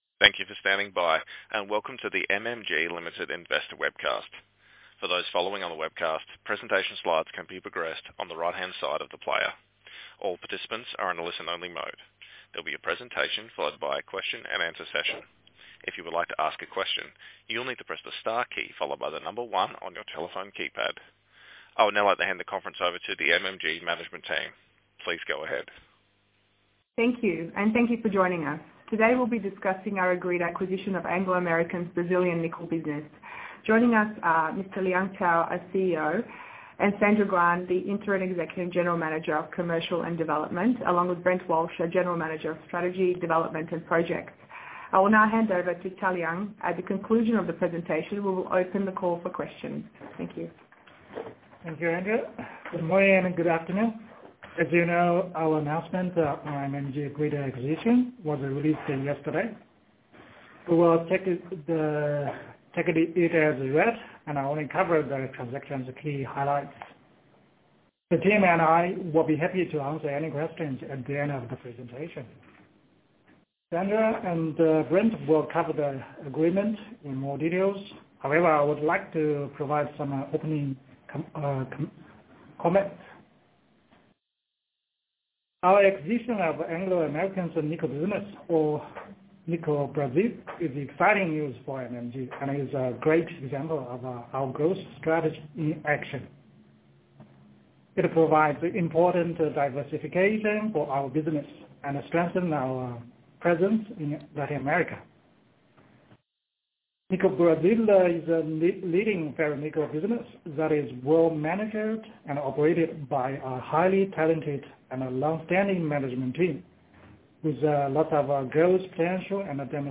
Acquisition of Nickel Brazil investor presentation recording